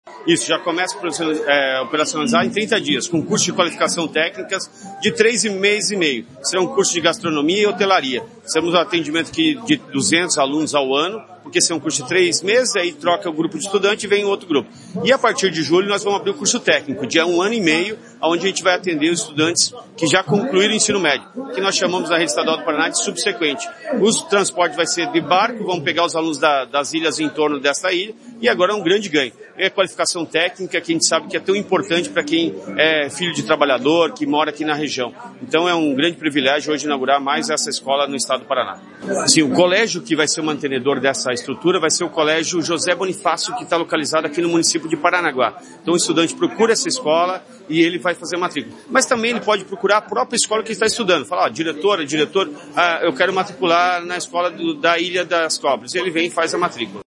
Sonora do secretário da Educação, Roni Miranda, sobre a Escola do Mar no Litoral